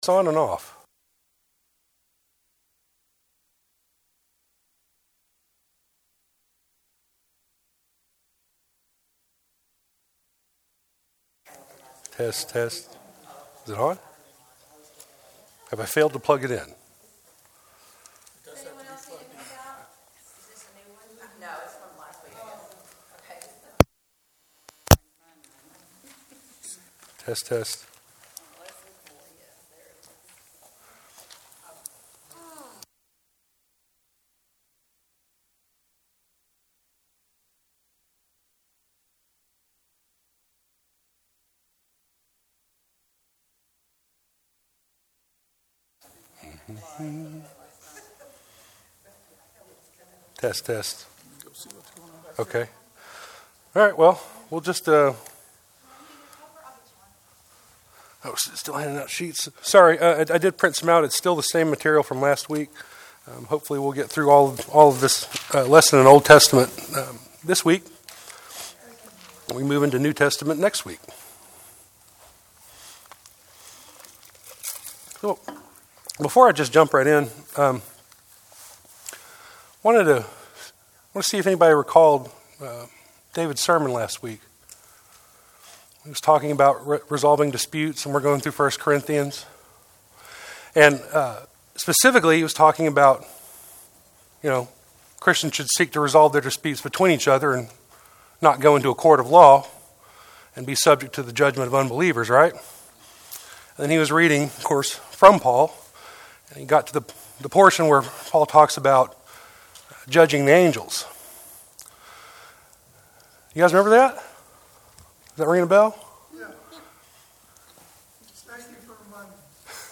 Angels in the OT Narrative Part II Preacher